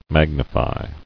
[mag·ni·fy]